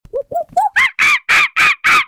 Monkey Noise Sound Effect